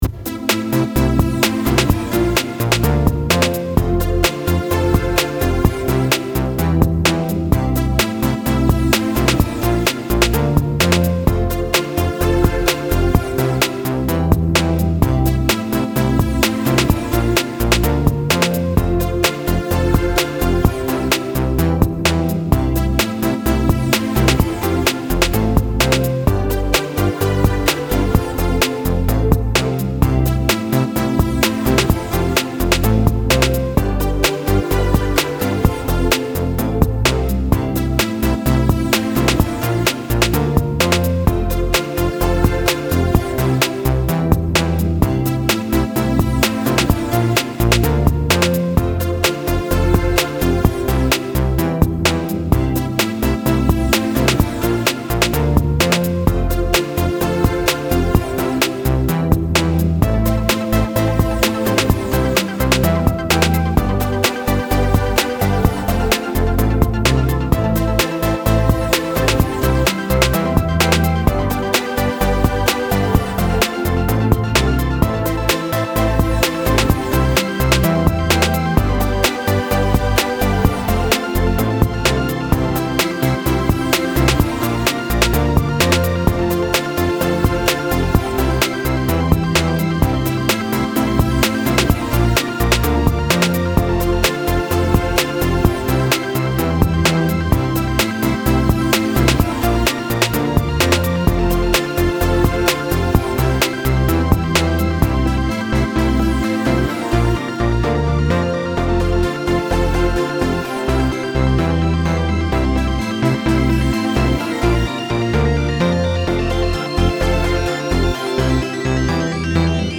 I used a few imported phrases, some example phrases, buit in chord progressions and some manual recording.